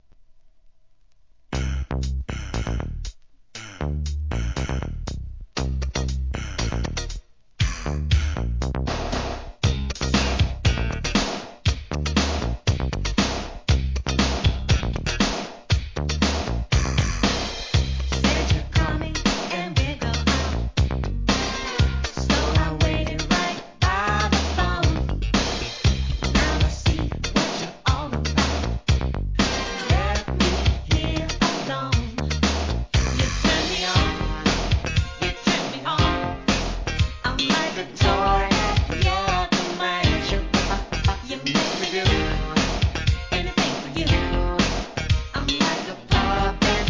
SOUL/FUNK/etc...
長女らしく渋いファンクチューン‼